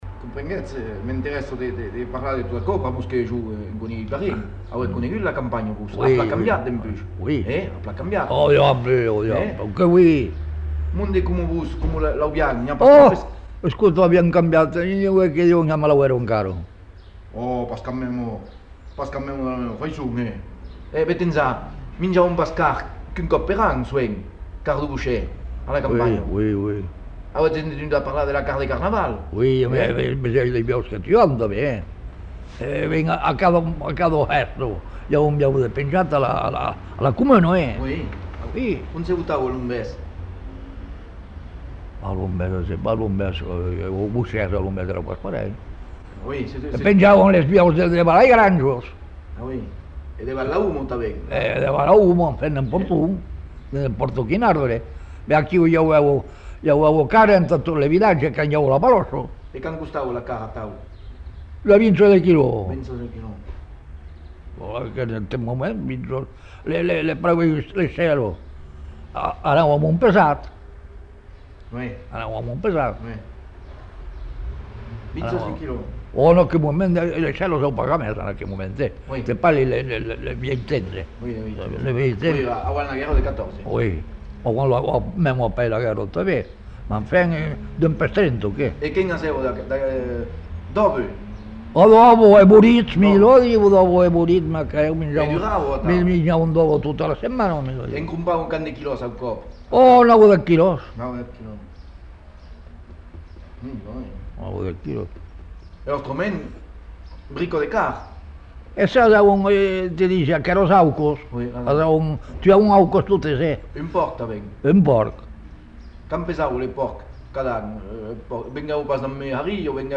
Aire culturelle : Savès
Genre : témoignage thématique